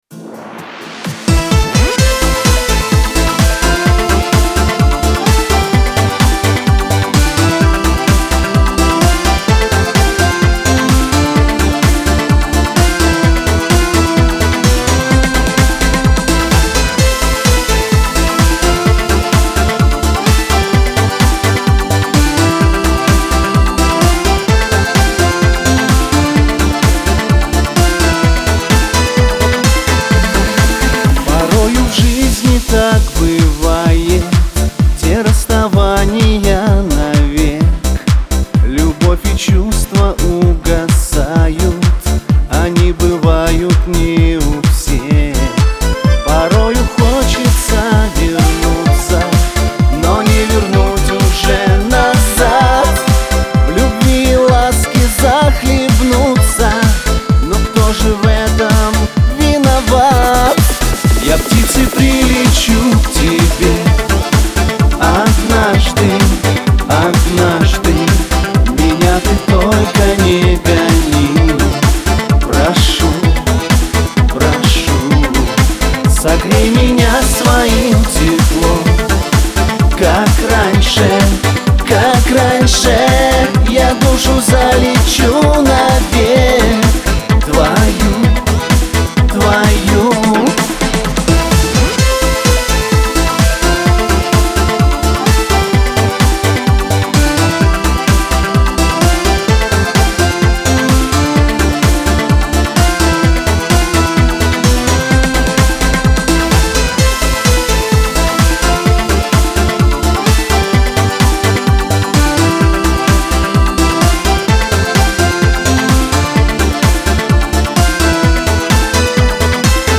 Лирика
диско